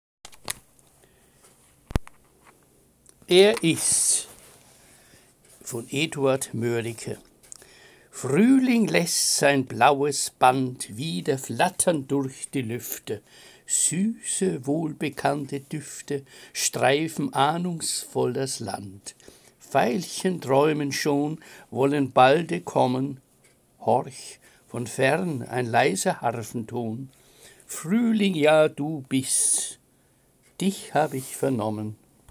Lesung Gedichte aus der Romantik